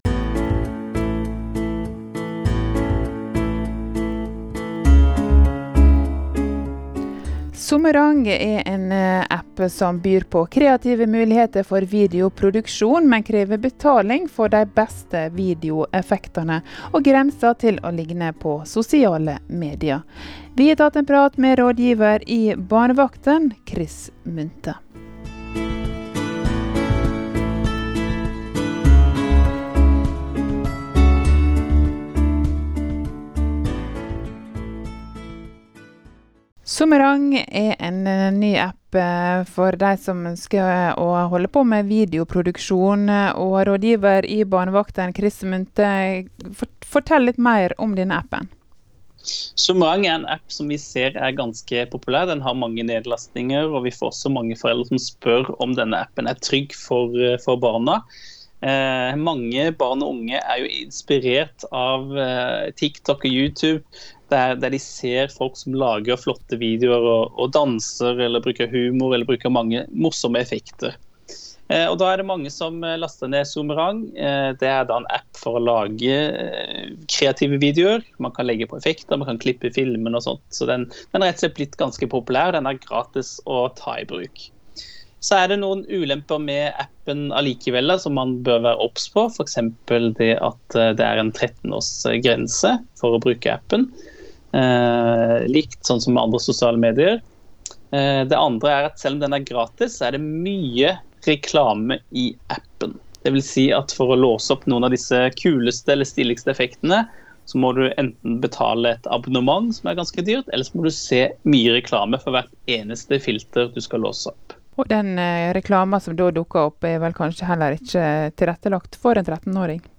i samtale med programleder